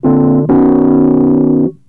01 Rhodes 1.wav